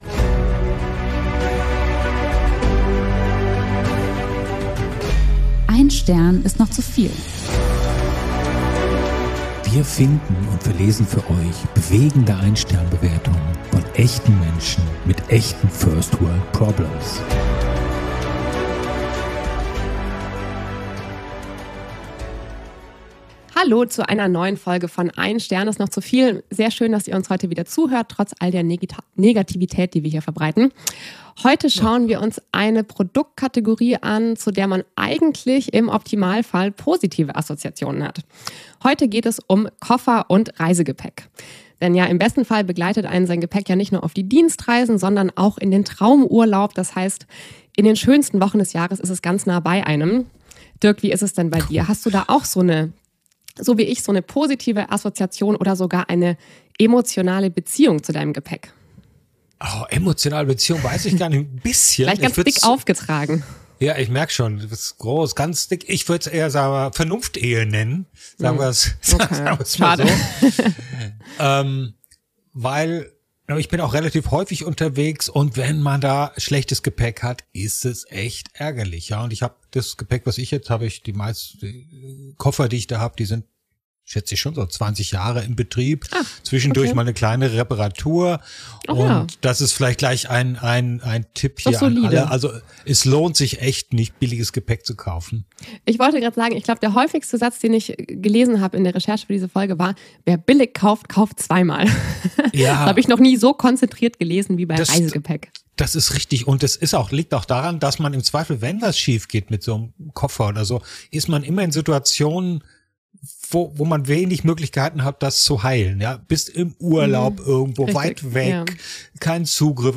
Komödie